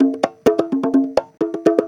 Conga Loop 128 BPM (8).wav